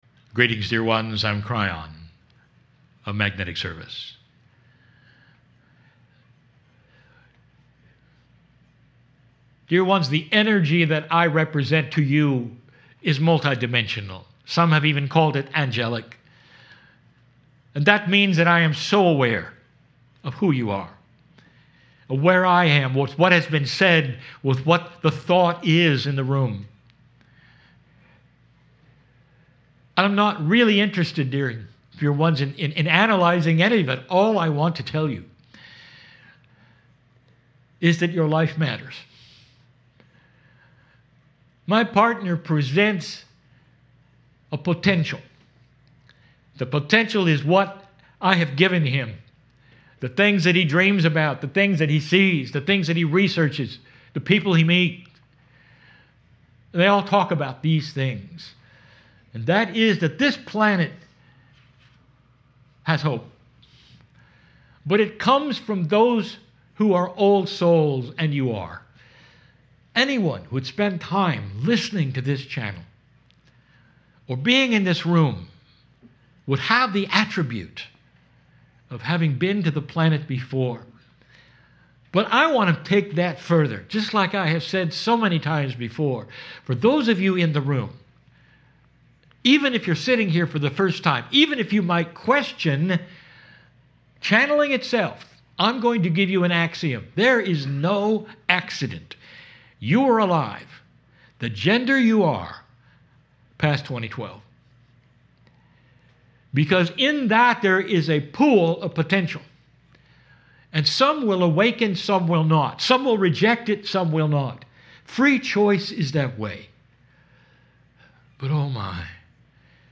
AWAKENINGS BOOK STORE - KRYON HOME ROOM
KRYON CHANNELLING